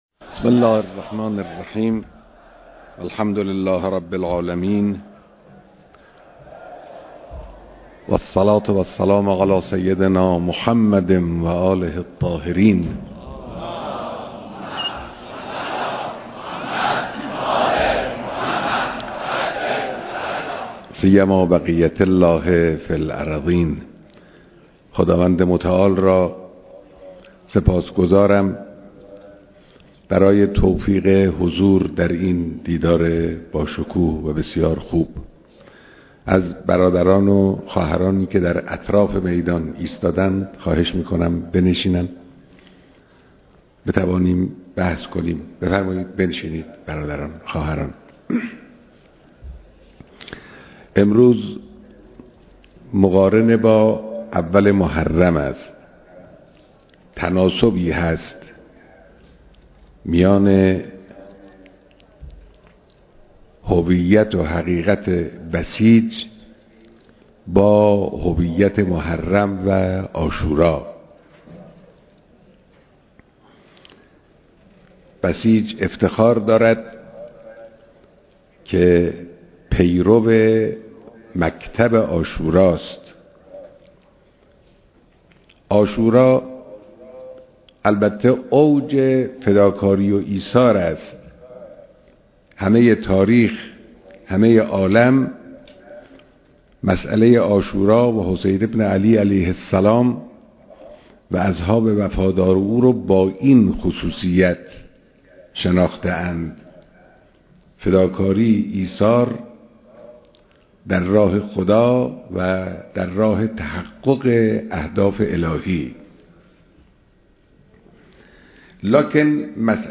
بيانات رهبر انقلاب در دیدار اقشار نمونه ی بسیج سراسر كشور